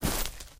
46265b6fcc Divergent / mods / Soundscape Overhaul / gamedata / sounds / material / human / step / tmp_default3.ogg 21 KiB (Stored with Git LFS) Raw History